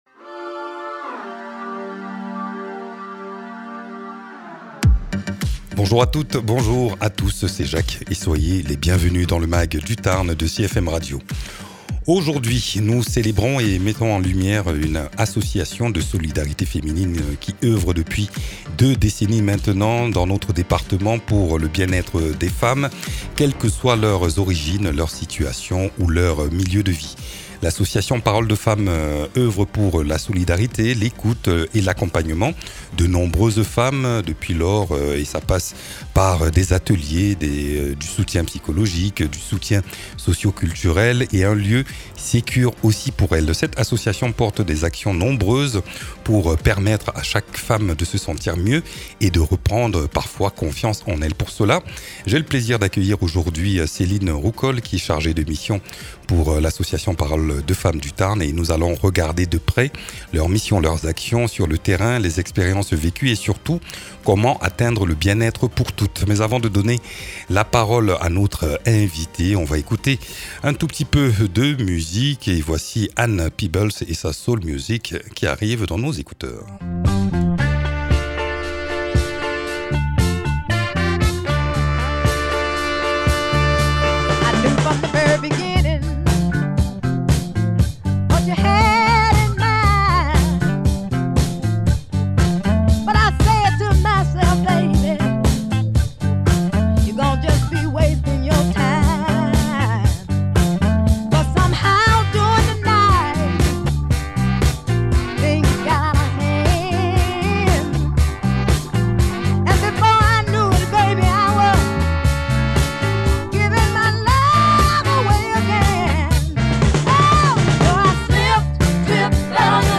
Une rencontre radiophonique forte et inspirante pour comprendre comment la parole, la solidarité et la sororité peuvent changer des vies.